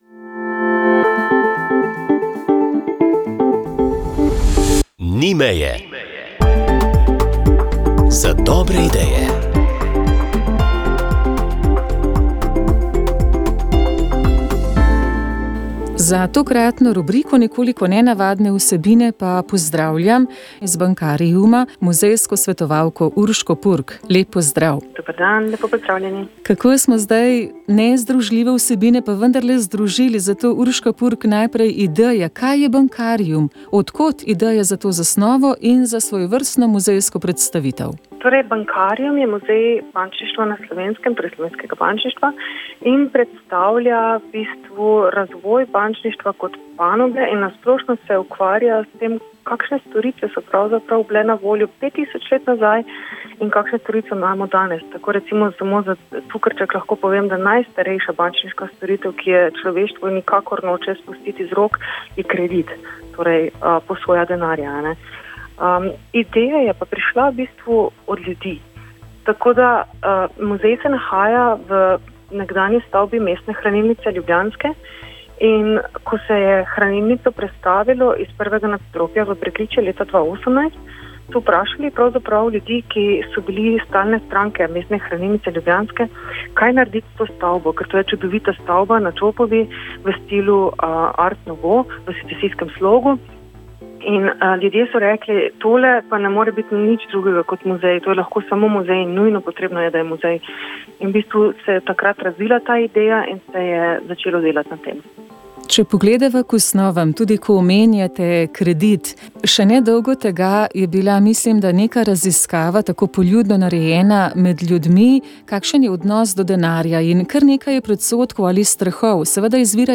Finančna pismenost bi lahko bila boljša, kot dobro idejo smo povabili k ogledu in obisku Bankariuma, kjer se seznanimo s finančnimi-denarnimi tokovi v preteklosti vse do danes. Pogovarjali smo se